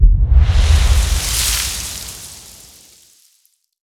fx_explosion_impact_water_01.wav